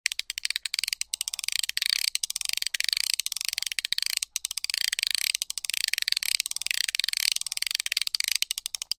Звуки удочки
Удочка - Альтернативный вариант